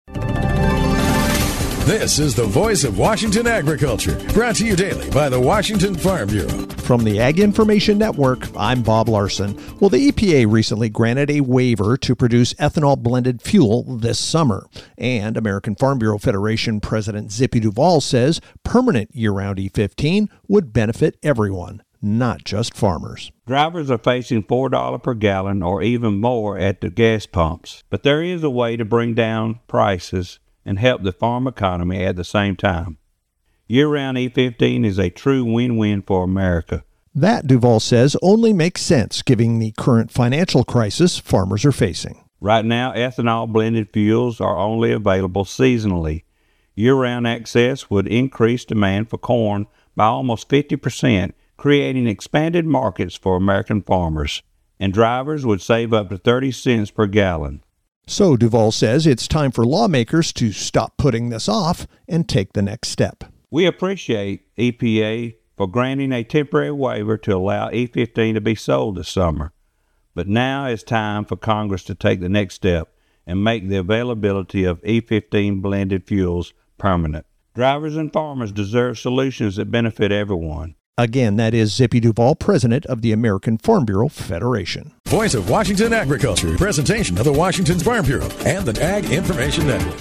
Tuesday Apr 14th, 2026 70 Views Washington State Farm Bureau Report